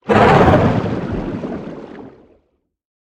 Sfx_creature_pinnacarid_rideoff_01.ogg